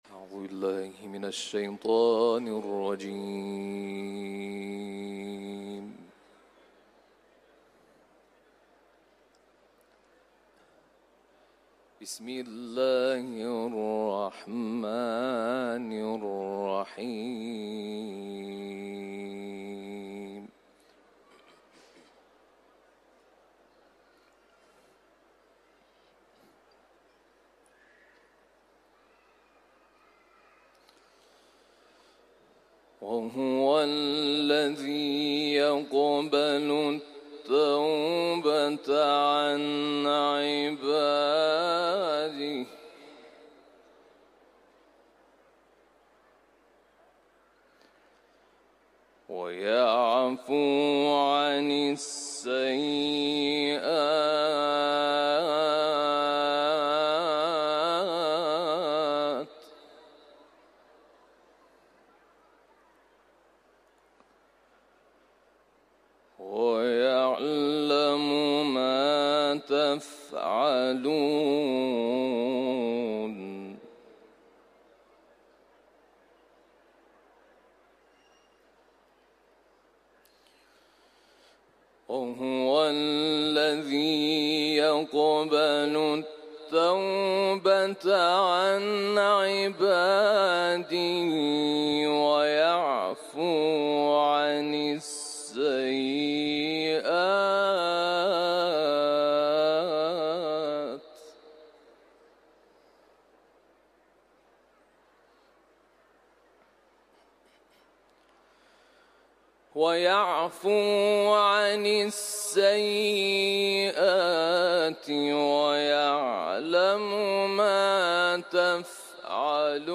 Kur’an tilaveti